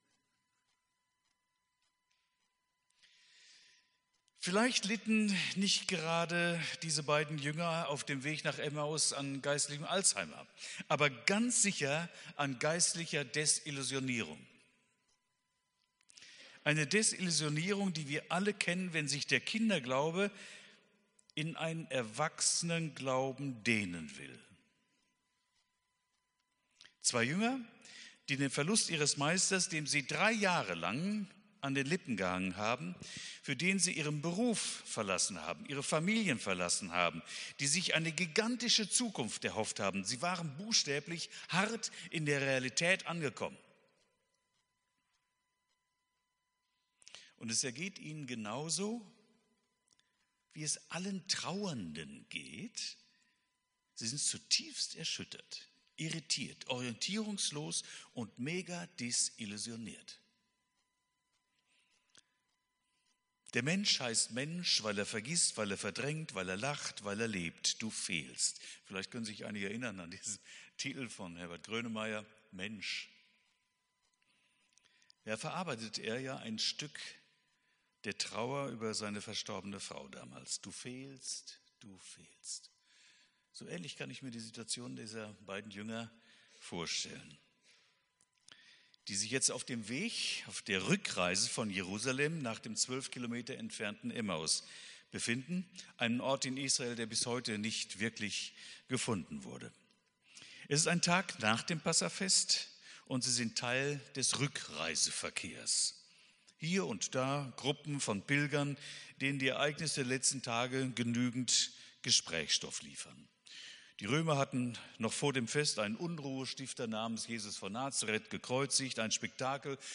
Predigt vom 27.04.2025